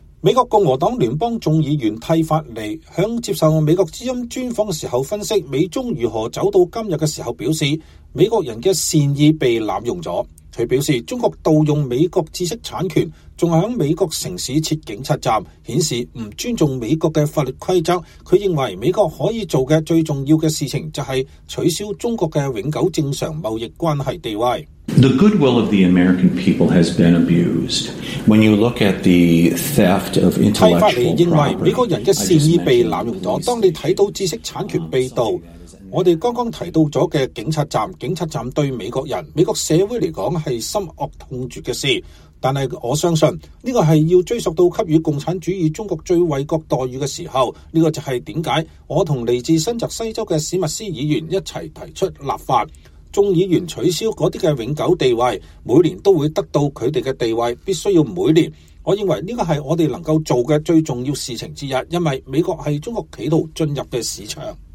專訪共和黨眾議員蒂法尼：應該取消中國的永久正常貿易關係地位